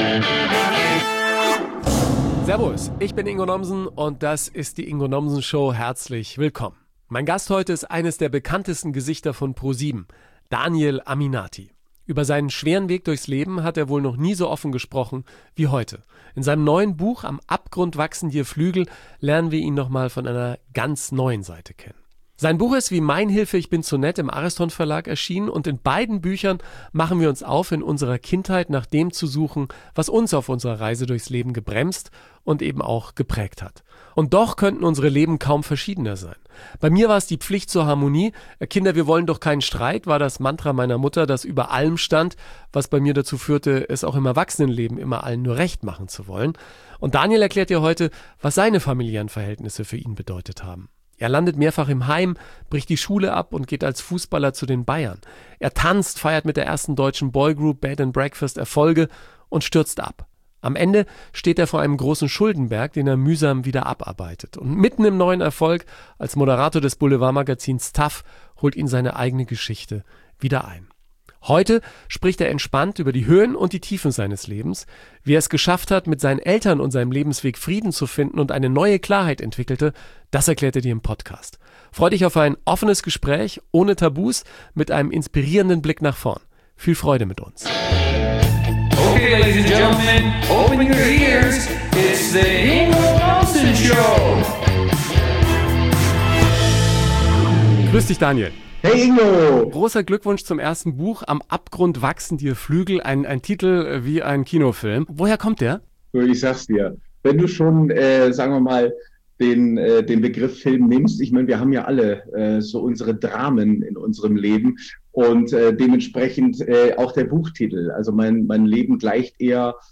Heute spricht er entspannt über die Höhen und Tiefen seines Lebens.
Freue Dich auf ein offenes Gespräch ohne Tabus - und einen inspirierenden Blick nach vorne.